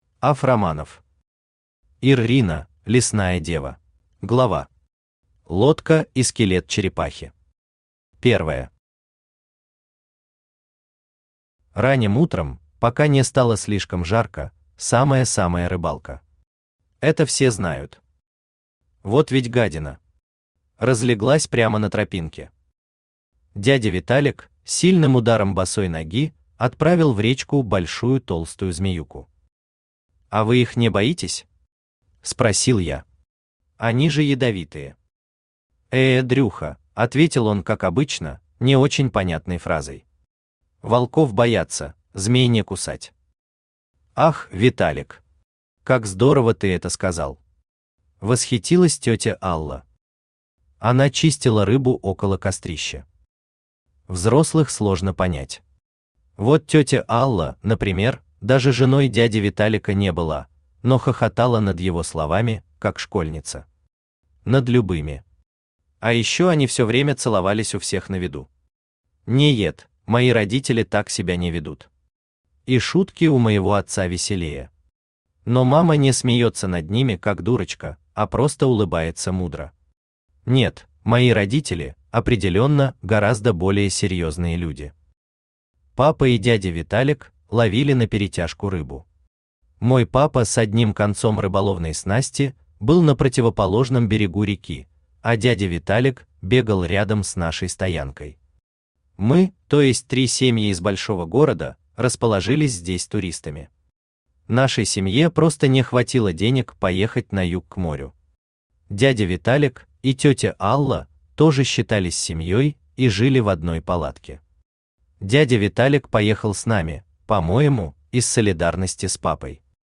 Aудиокнига Ир-рина – лесная дева Автор АВ Романов Читает аудиокнигу Авточтец ЛитРес.